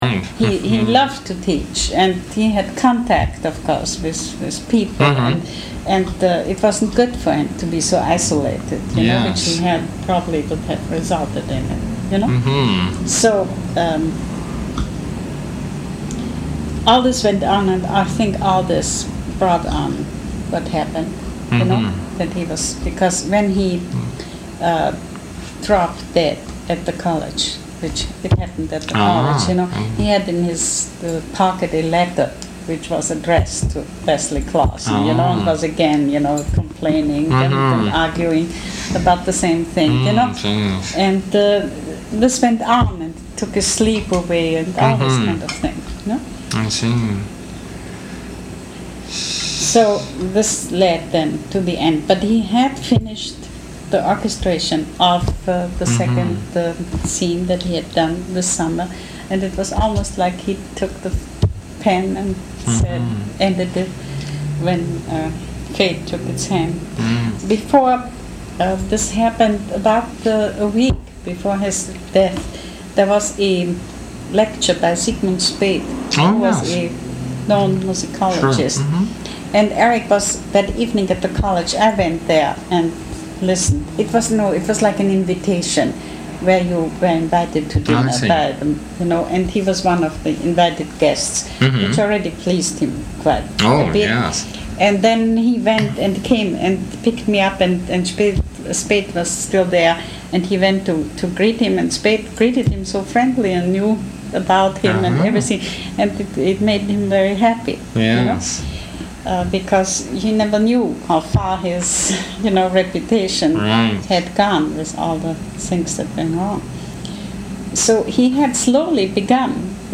Interviews-Lectures